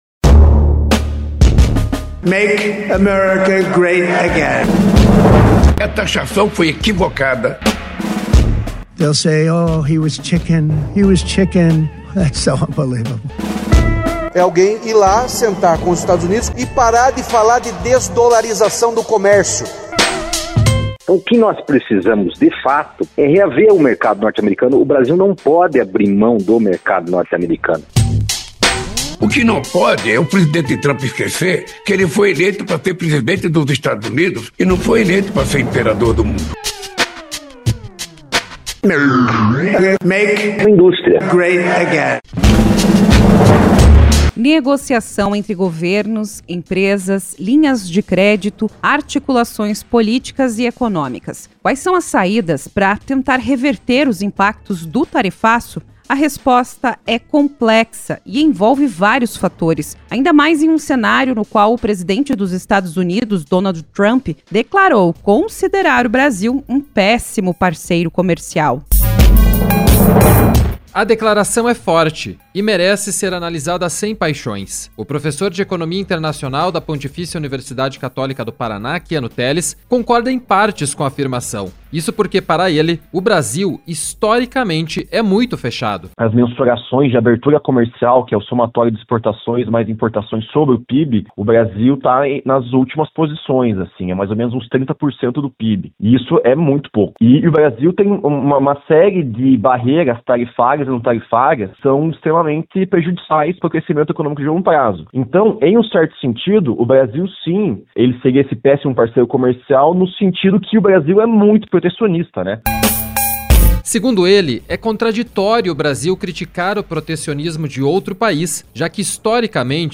Reportagem 4 – Negociação e ajuda comercial